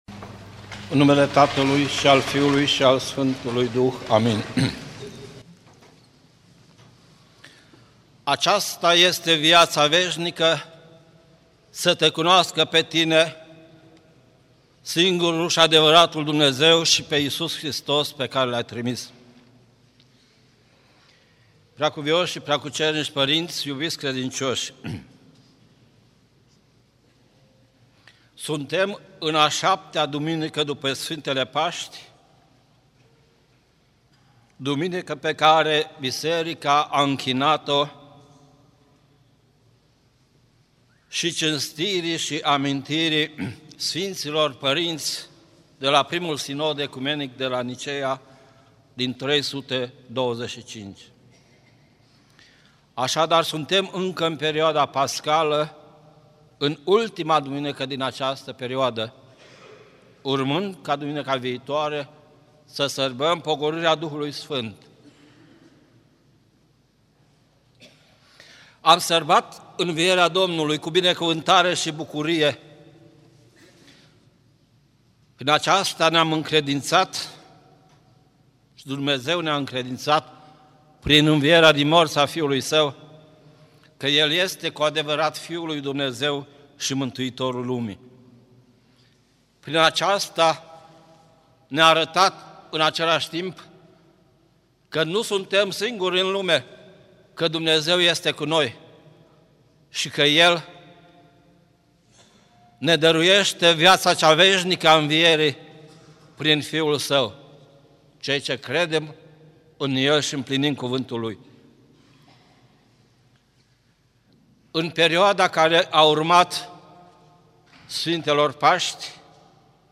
Cuvinte de învățătură Predică la Duminica a 7-a după Paști